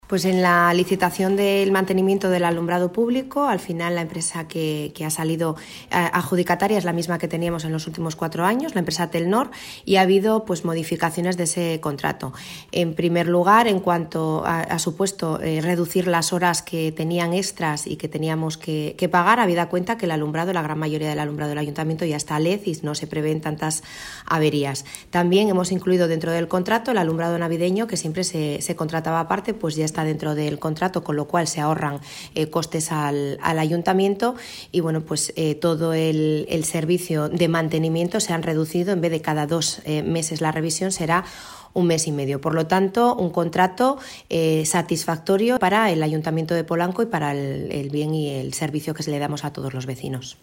Alcaldesa-sobre-mejoras-en-nuevo-contrato-de-alumbrado-publico.mp3